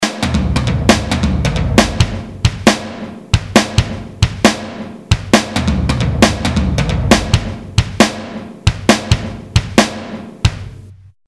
����� ����: VSTi Drums ��� ������ one shot - ��������� (��������� 2005 ���)